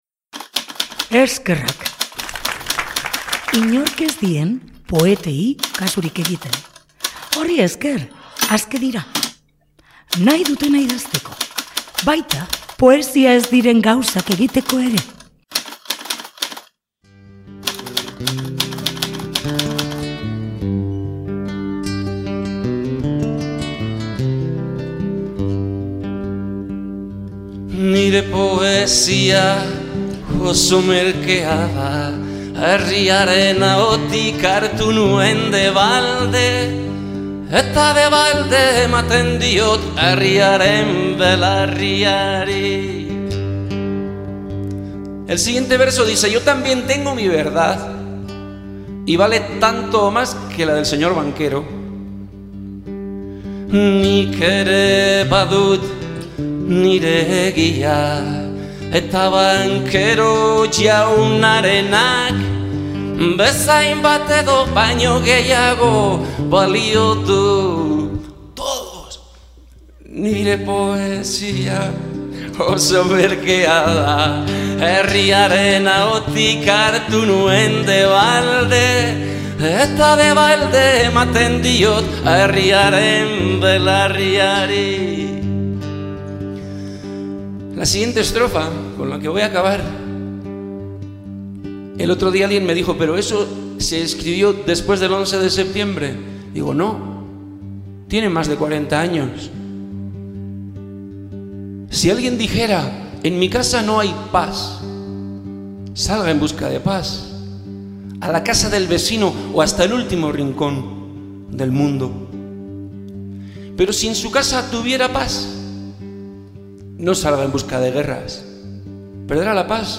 Beraiekin berba egin dugu.